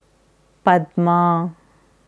Padmaa पद्मा padmā Aussprache
Hier kannst du hören, wie das Sanskritwort Padmaa, पद्मा, padmā ausgesprochen wird: